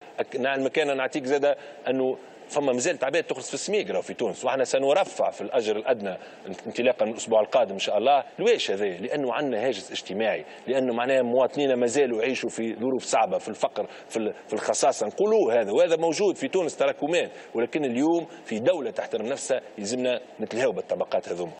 أكد رئيس الحكومة يوسف الشاهد حوار خاص على قناة الحوار التونسي مساء اليوم الأحد أنه سيتم الترفيع في الأجر الأدنى إنطلاقا من الأسبوع القادم.